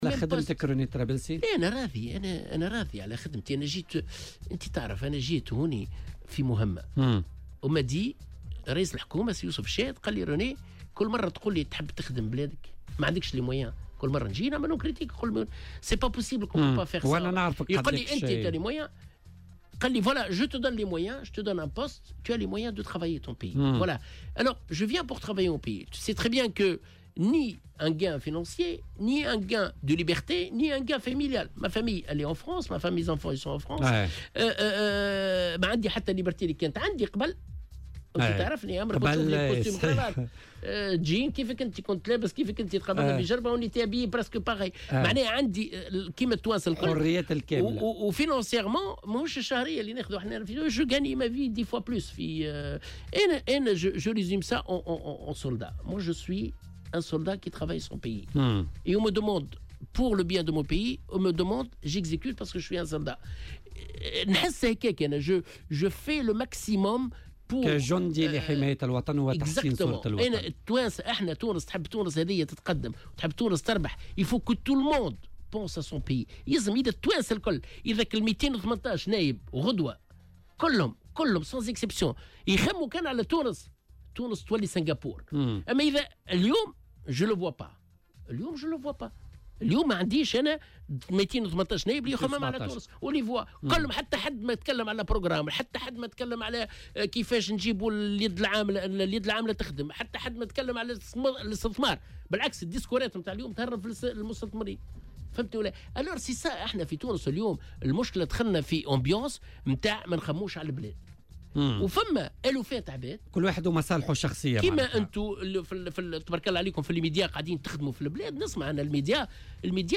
وأوضح خلال مداخلة له اليوم في برنامج "بوليتيكا" على "الجوهرة أف أم" أنه راض عن كُلّ ما قدّمه وما قام به خلال فترة توليه لمنصبه.